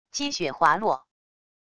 积雪滑落wav音频